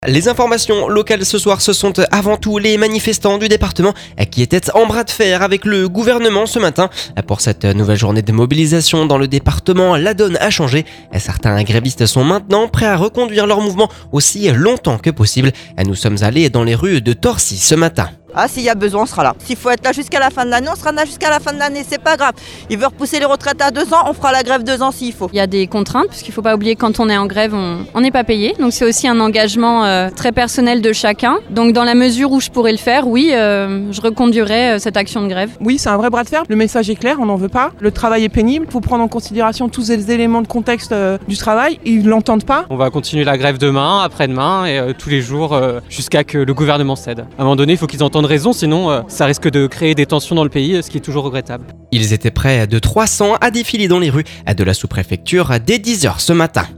Nous sommes allés dans les rues de Torcy ce matin…